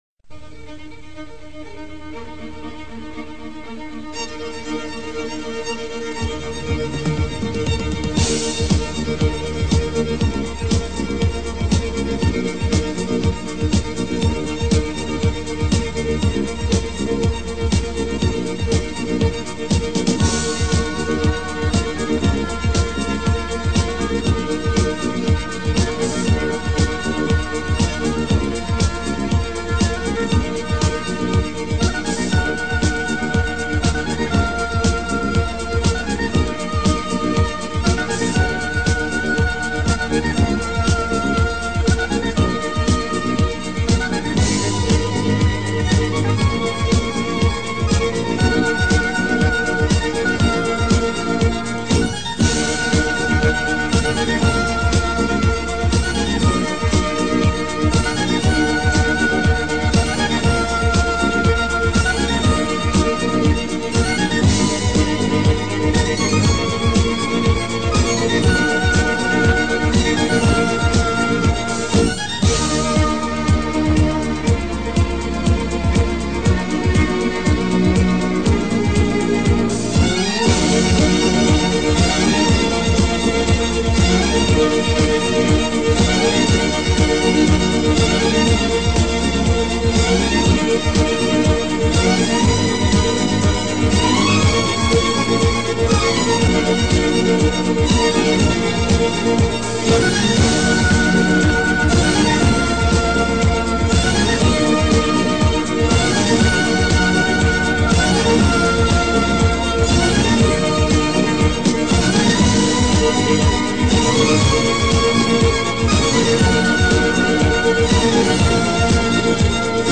Genre:Neo-Classical,Modern Classical